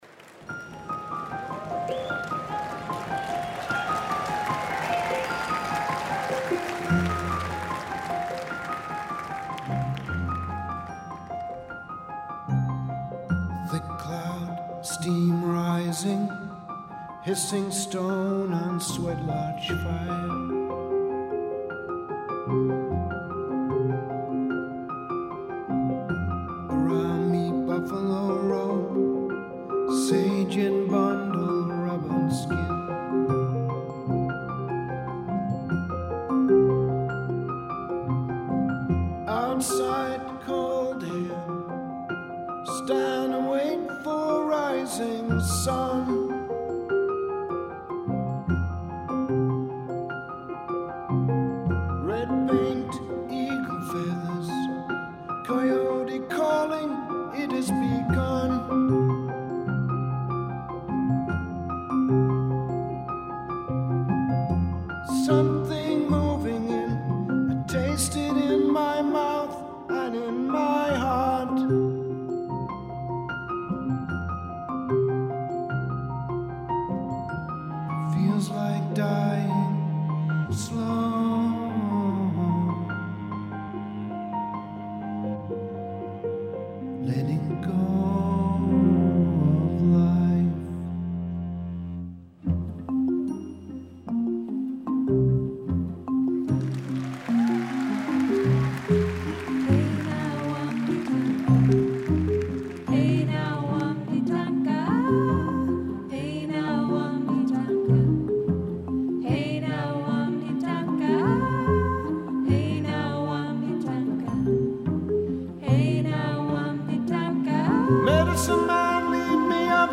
was performed in London March 2011